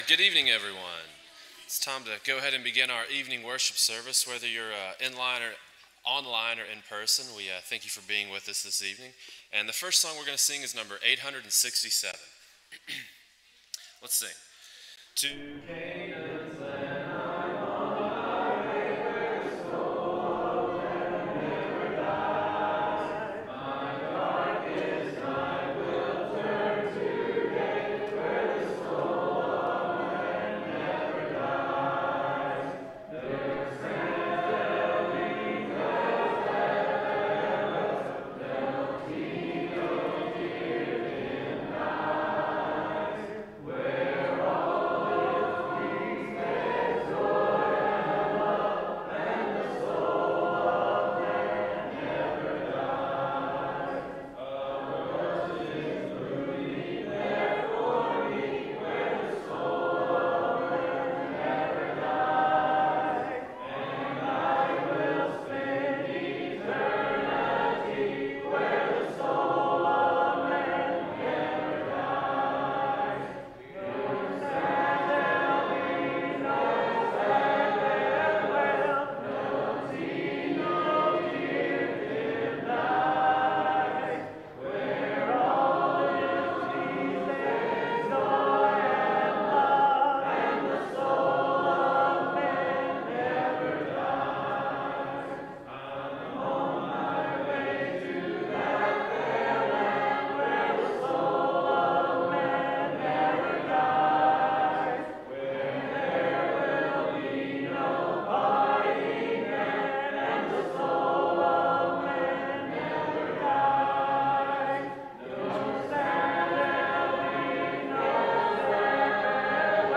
Exodus 14:14, English Standard Version Series: Sunday PM Service